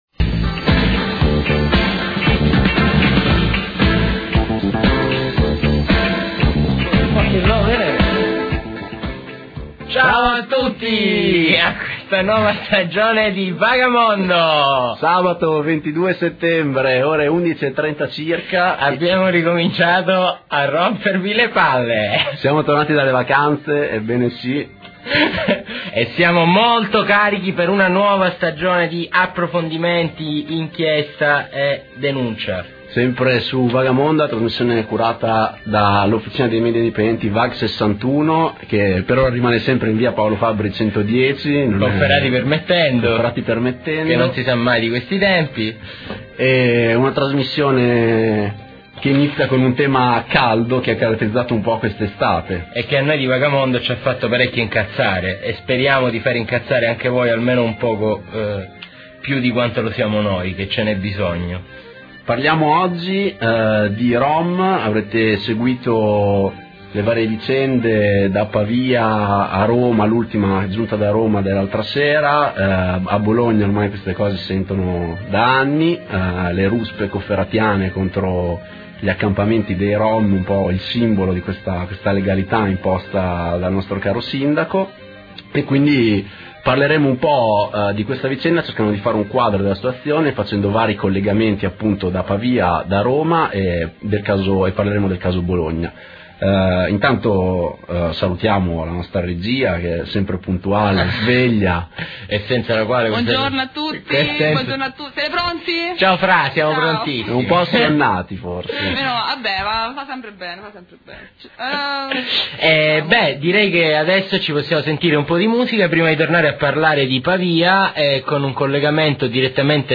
Vag61 - Scarica la puntata del 22 settembre '07 - Puntata dedicata alla "caccia ai rom" scatenatasi a Bologna e in un numero sempre maggiore di città italiane. Interviste